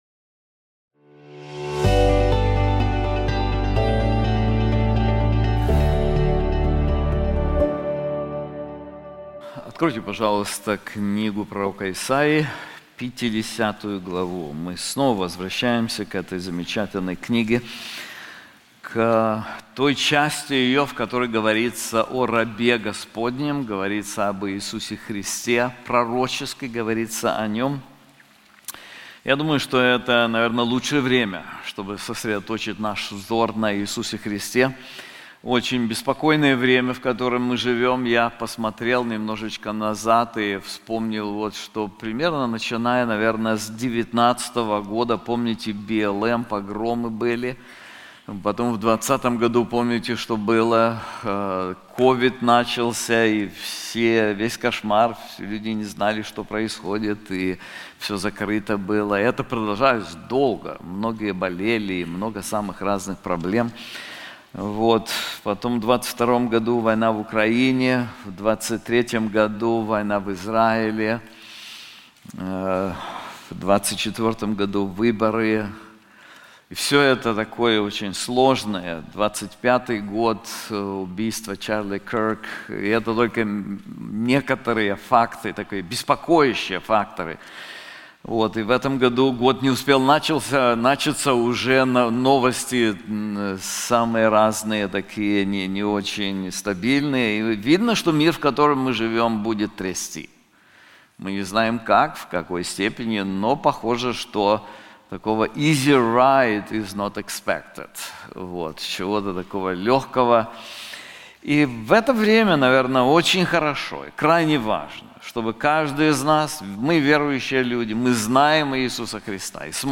В изучаемом отрывке Раб Господень объясняет практику своего победного упования на Господа. Об этом будем говорить в этой проповеди.